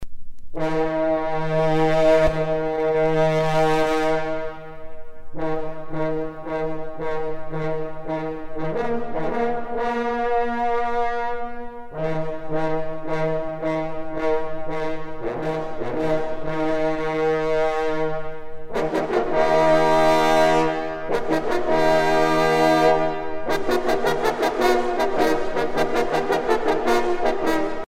trompe - Fanfares et fantaisies de concert
circonstance : vénerie
Pièce musicale éditée